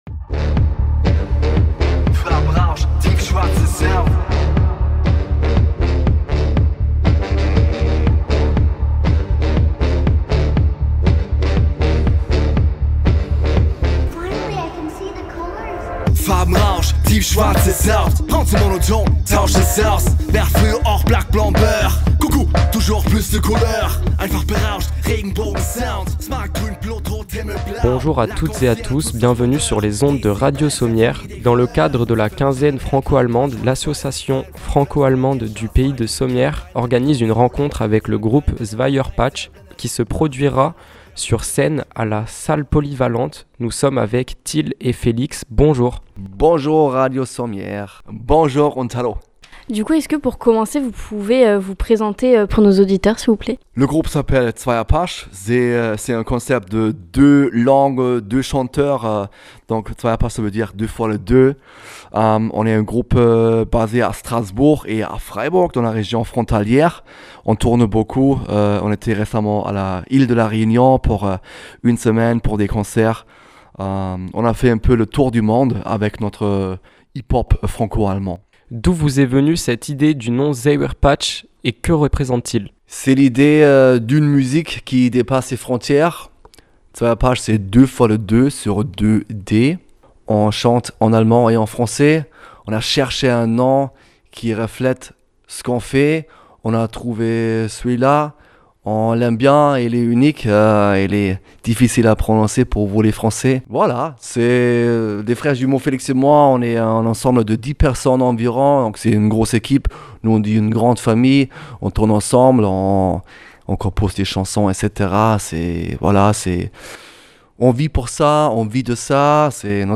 Interview du groupe de Rap Franco-Allemand ZWEIERPASCH
Retrouvez l'interview du groupe de Hiphop Franco-Allemand "ZWEIERPASCH" .